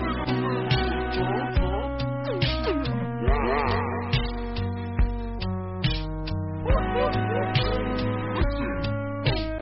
Robot running on 2% battery sound effect
Robot-running-on-2-battery-sound-effect.mp3